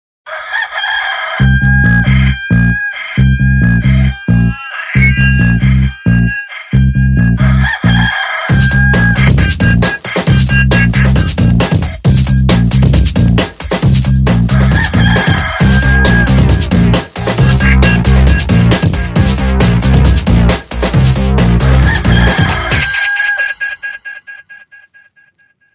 Index of /phonetones/unzipped/Micromax/Bolt-Warrior-1-Plus-Q4101/alarms
Rooster_Rumble.mp3